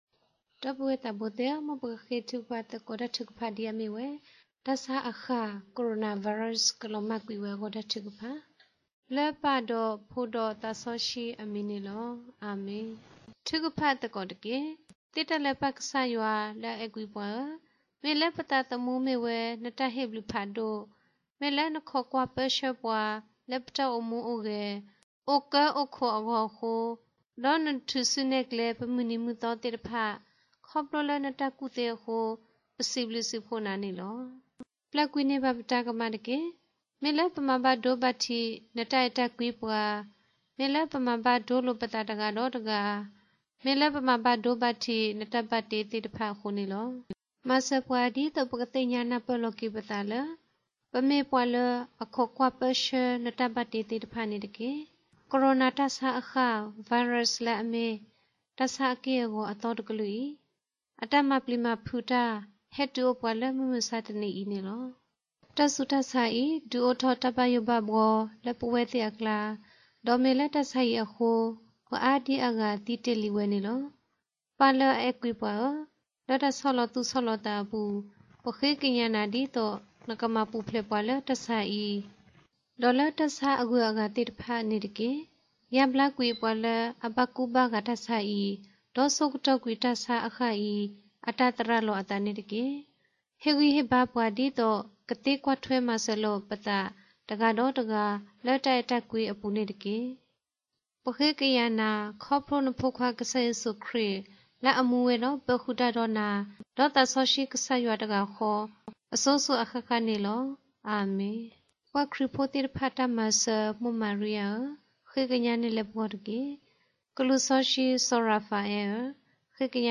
corona_virus_prayer_audio_only.mp3